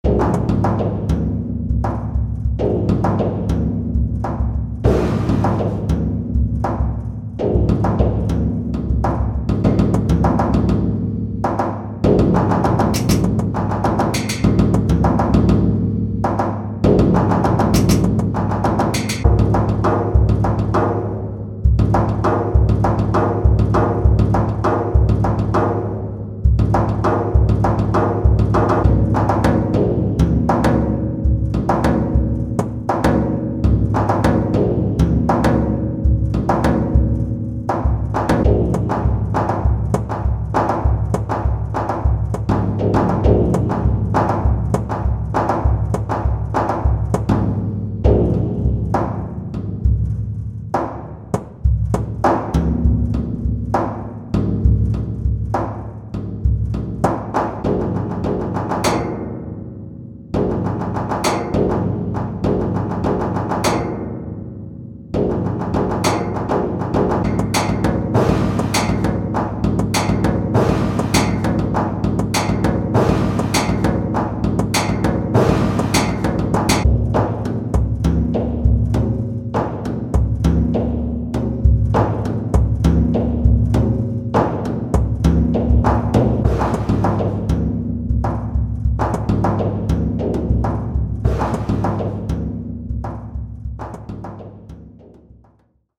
We delved into a derelict basement and banged on everything we could find with a drum stick to create this exclusive and experimental kit – try combining with real drums for add a unique flavour to your sound.